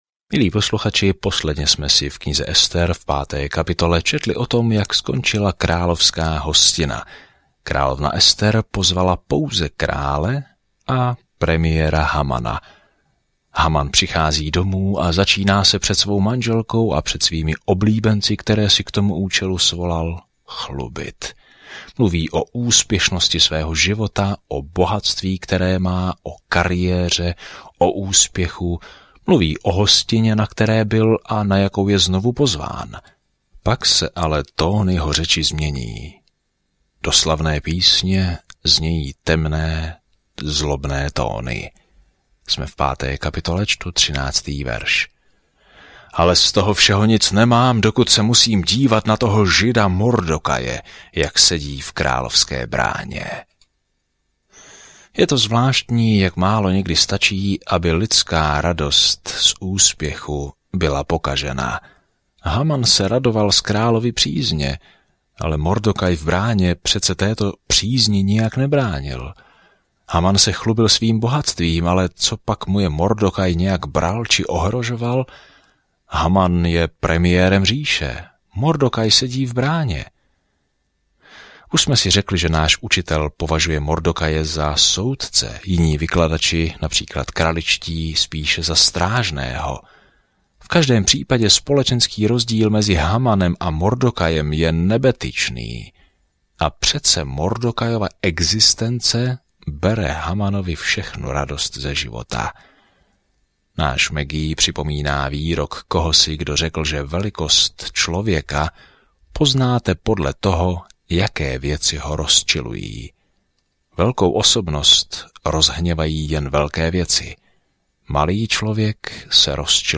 Denně procházejte Ester a poslouchejte audiostudii a čtěte vybrané verše z Božího slova.